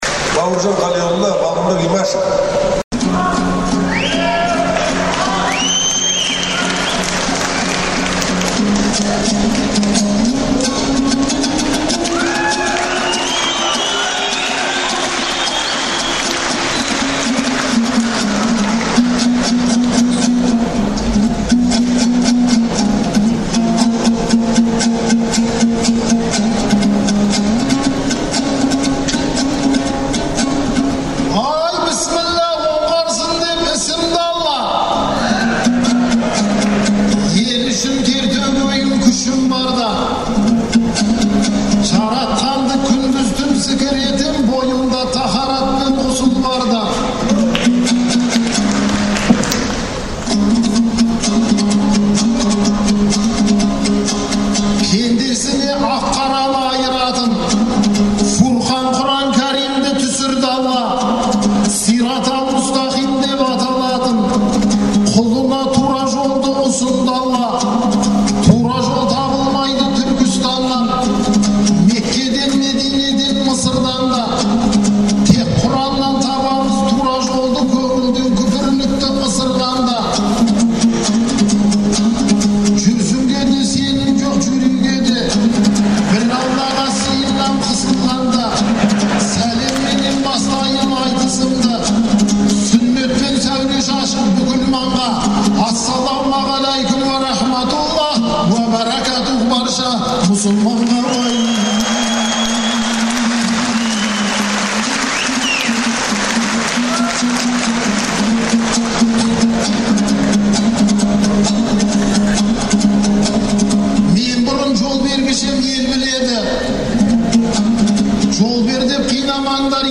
Алматы қаласындағы Балуан Шолақ атындағы спорт сарайында ақпанның 11-і мен 12-сі аралығында "Қонаевтай ер қайда" деген атпен айтыс өтті. Дінмұхамед Қонаевтың туғанына 100 жыл толуына орай ұйымдастырылған сөз сайысына он сегіз ақын қатысты. Айтыскерлер Жаңаөзен оқиғасын да сөз етті.